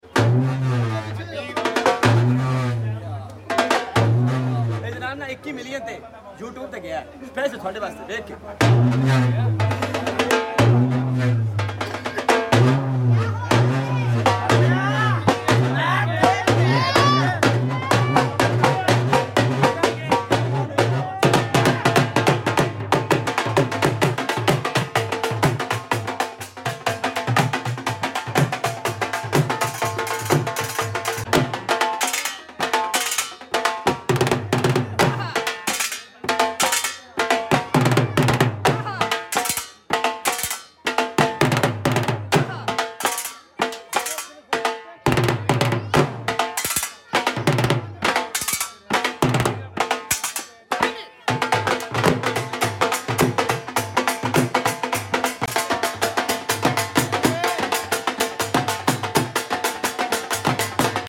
beautiful Dhol beat